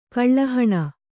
♪ kaḷḷa haṇa